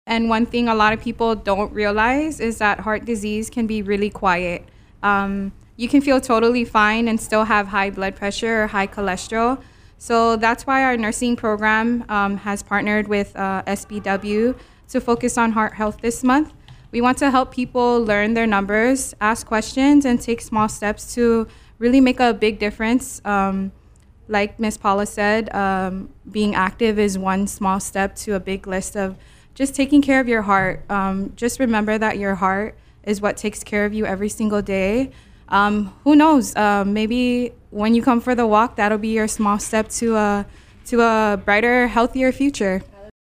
Nursing student